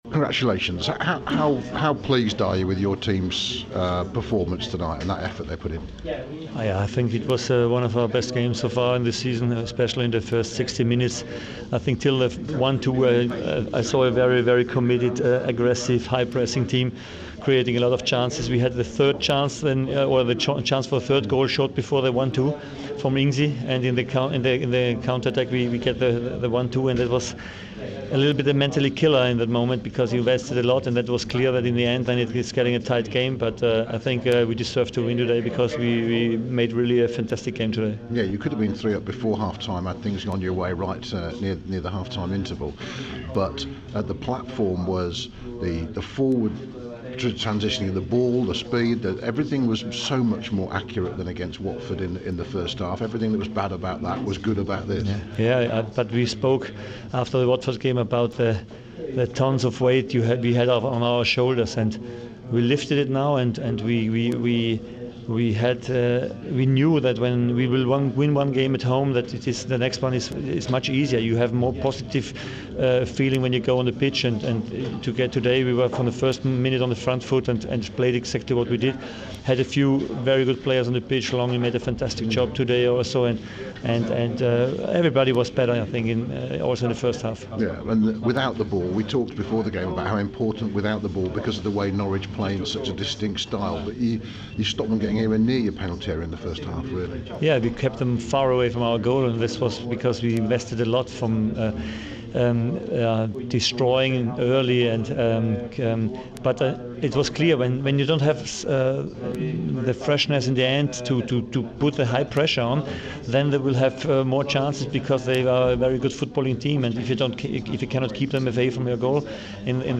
Ralph Hasenhüttl speaking after the 2-1 win over Norwich.